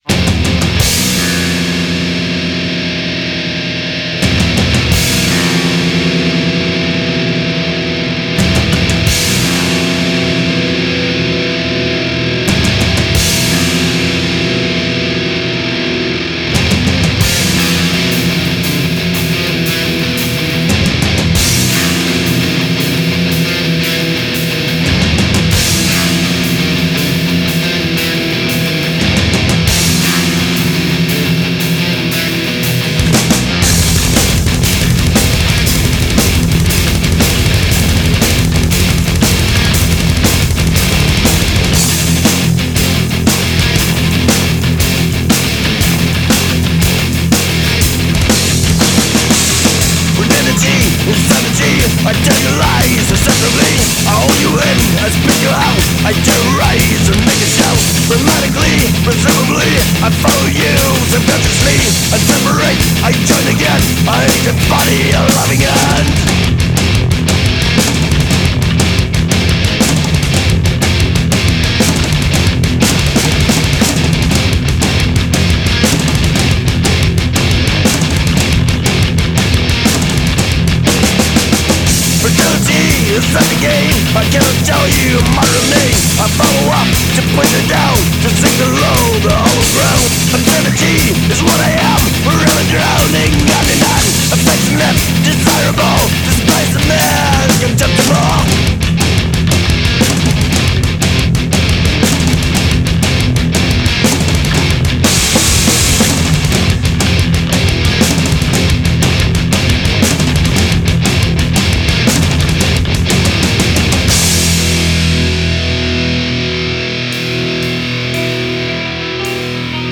Recorded January 2004 at Skansen Lydstudio, Trondheim.